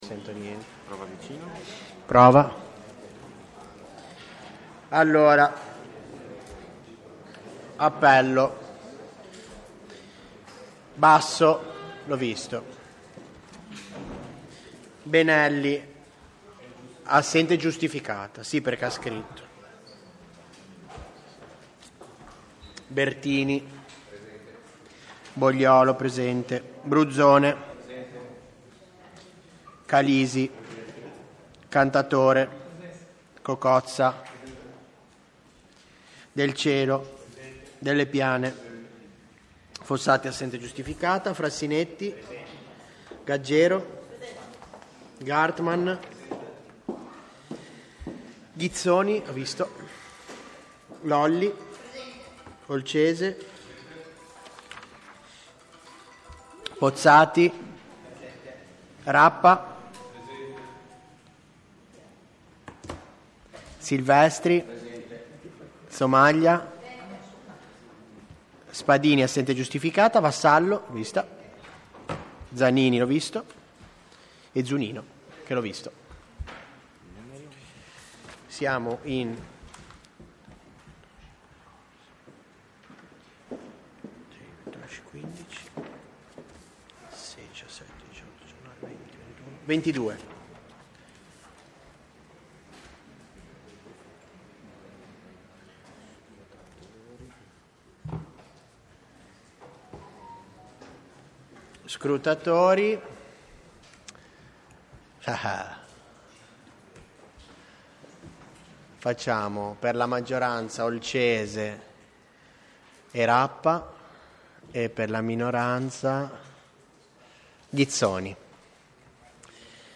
Seduta di Consiglio del Municipio IX | Comune di Genova
La seduta si tiene nella Sala Consiliare del Municipio Levante in Via Domenico Pinasco 7 Canc.